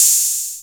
808-OpenHiHats16.wav